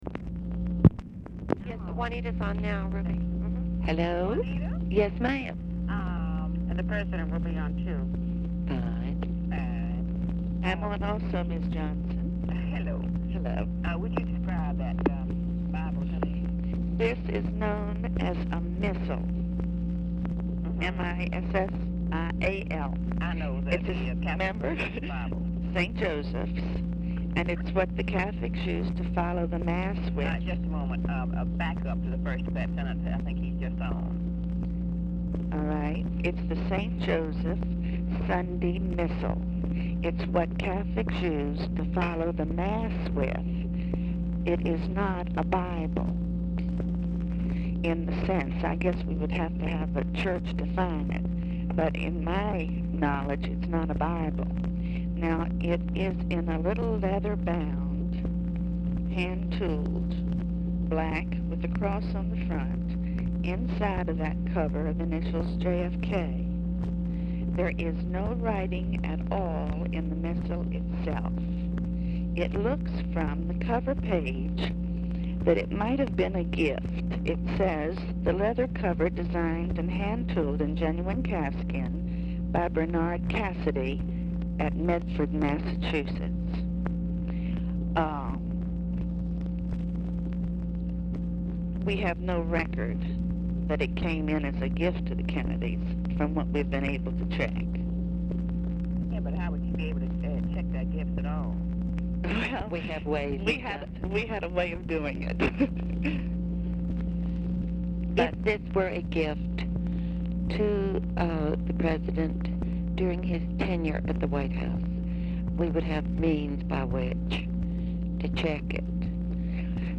Telephone conversation
THIS BELT RECORDED AT WH; POOR SOUND QUALITY
Dictation belt